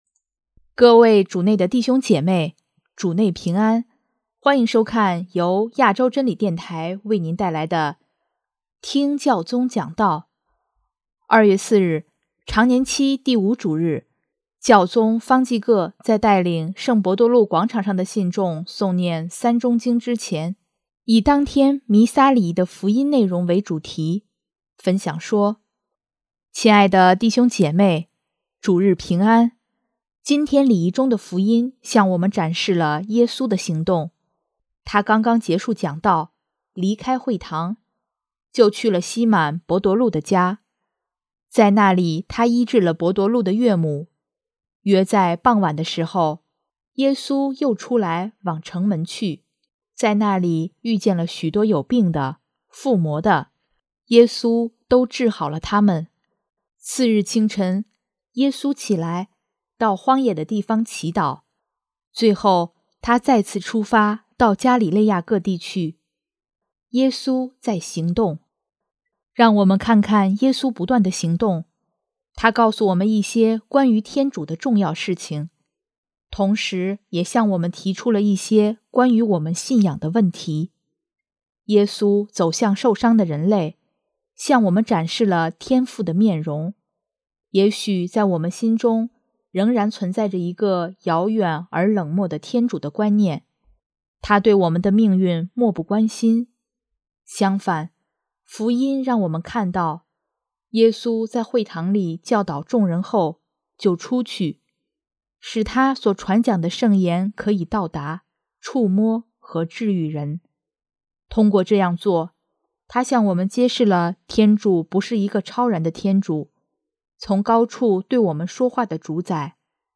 【听教宗讲道】|天主不是高高在上的主宰，祂是一位充满爱的父亲
2月4日，常年期第五主日，教宗方济各在带领圣伯多禄广场上的信众诵念《三钟经》之前，以当天弥撒礼仪的福音内容为主题，分享说：